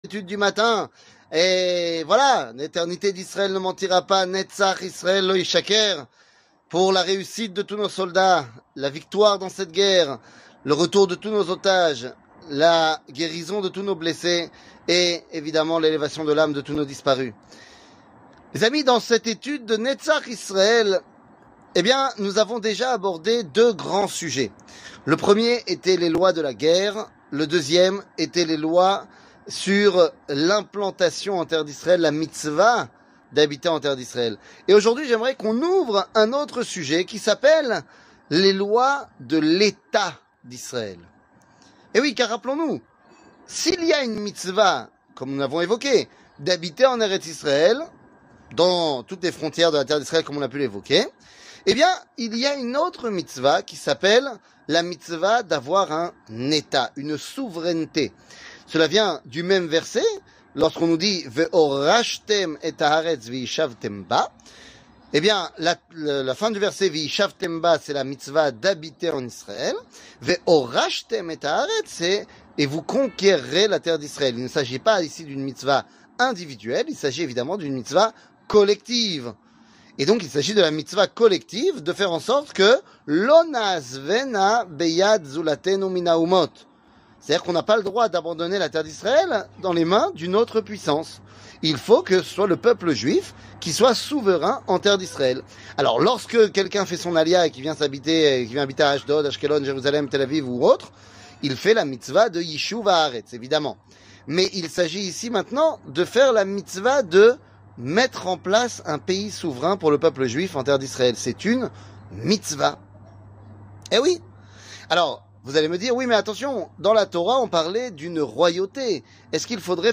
L'éternité d'Israel ne mentira pas ! 26 00:05:57 L'éternité d'Israel ne mentira pas ! 26 שיעור מ 12 נובמבר 2023 05MIN הורדה בקובץ אודיו MP3 (5.44 Mo) הורדה בקובץ וידאו MP4 (12.59 Mo) TAGS : שיעורים קצרים